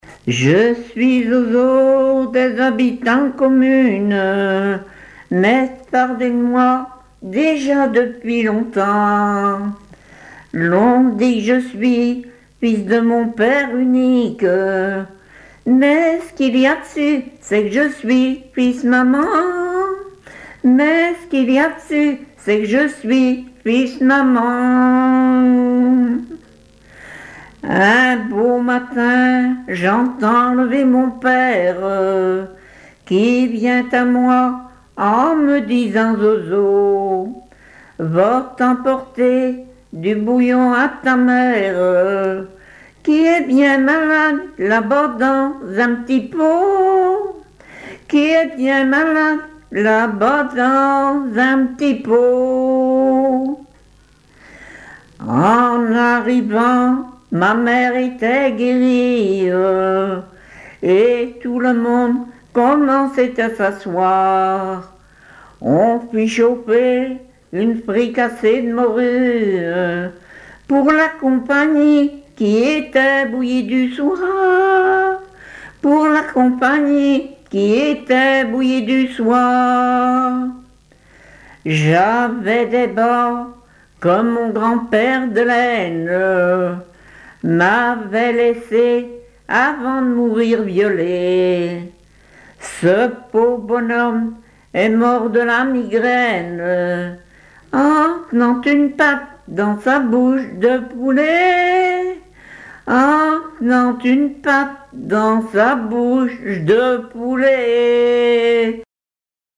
Mémoires et Patrimoines vivants - RaddO est une base de données d'archives iconographiques et sonores.
Genre strophique
Répertoire de chansons populaires et traditionnelles
Pièce musicale inédite